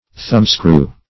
Thumbscrew \Thumb"screw`\, n.